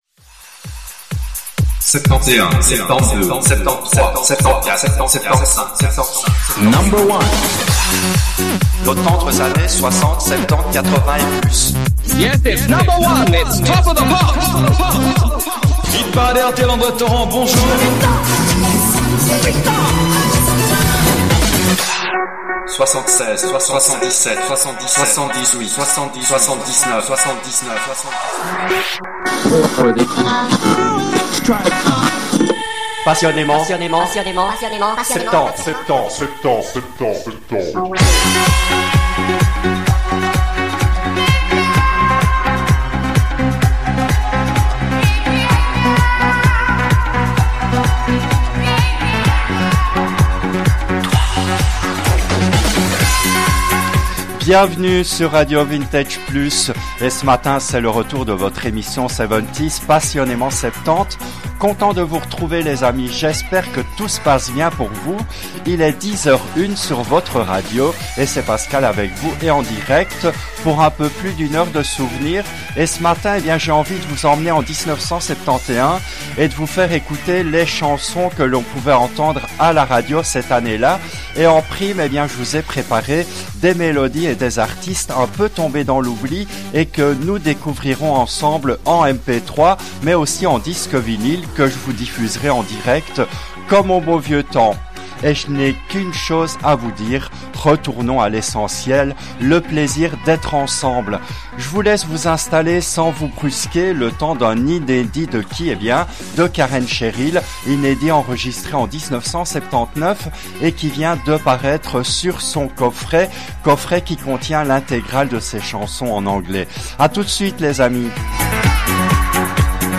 Cette émission a été diffusée en direct le jeudi 11 janvier 2024 à 10h depuis les studios belges de RADIO RV+.